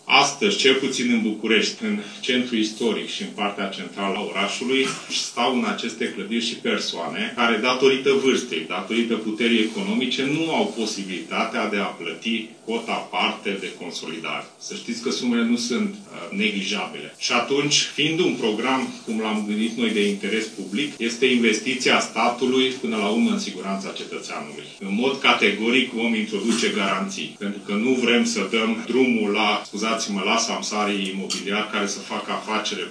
Va fi un program multianual, care va fi viza nu doar reabilitarea clădirilor de locuințe, ci și a instituțiilor publice, a spus ministrul Cseke Atilla, la o dezbatere organizată de Academia Română:
Ministrul Dezvoltării a mai spus cum i-ar putea ajuta statul pe proprietarilor de locuințe care nu au suficiente fonduri pentru plata lucrărilor de consolidare a clădirii: